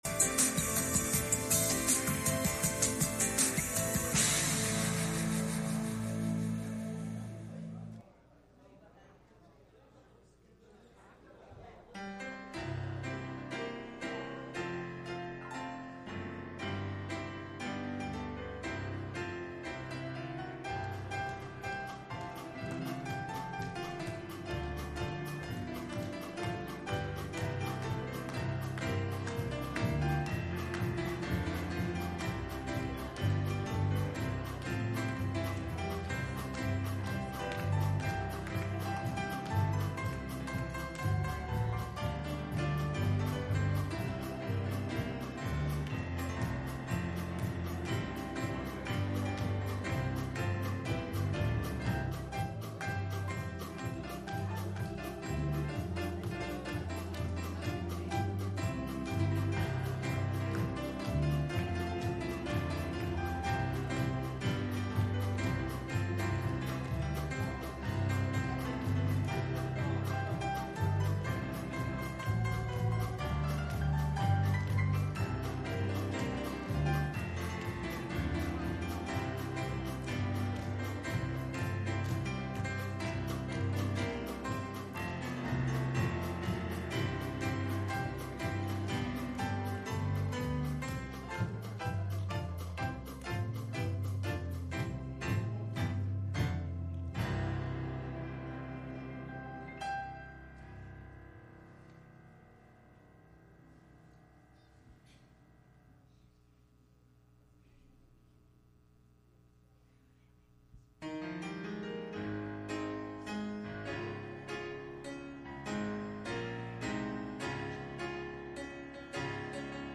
Exodus 16:4 Service Type: Sunday Morning « From Cradle To Cross